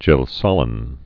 (jĕlsŏlĭn)